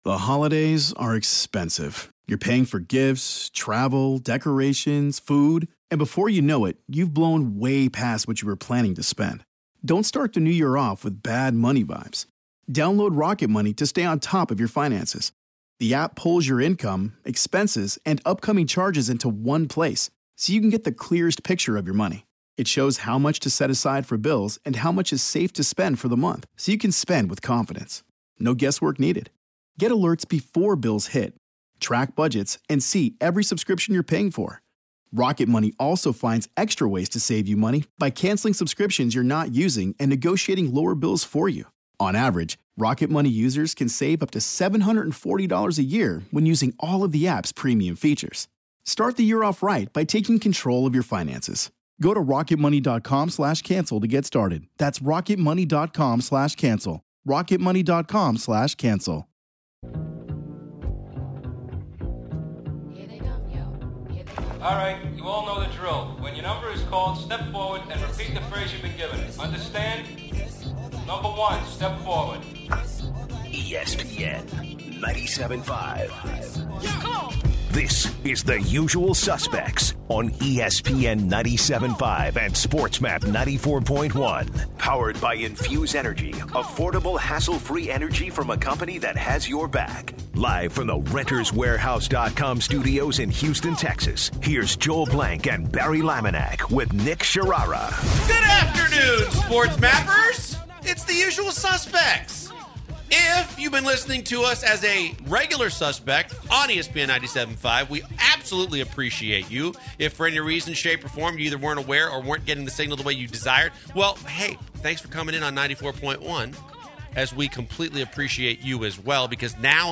They argue about whether or not Norah Jones should be considered for Way Back Wednesday. Throughout the show they take in calls about XSL, paying college athletes and picking up girls at concerts. They end the show with mentioning a story about how a member from Color Me Badd pushed another singer off the stage in the middle of their show.